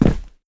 jumpland5b.wav